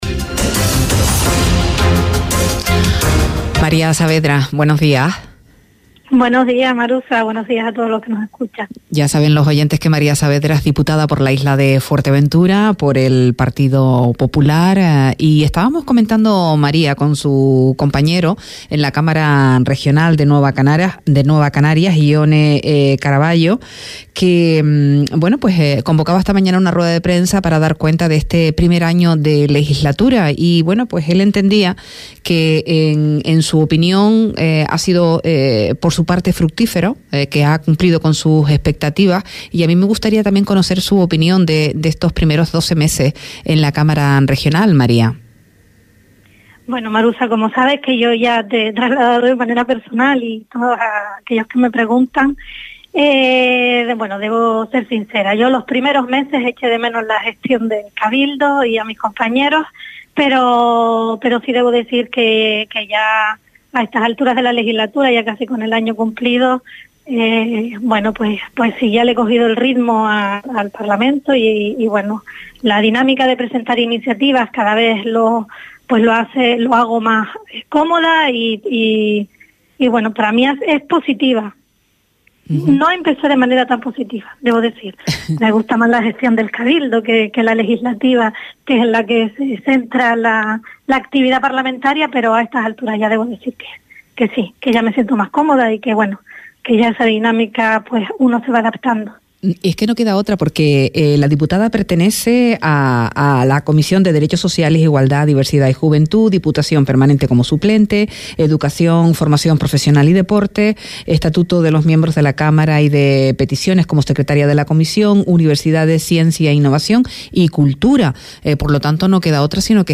La diputada del Partido Popular por Fuerteventura María Saavedra intervino hoy en 'Parlamento', donde explicó su intervención en la Cámara regional en materia de vivienda.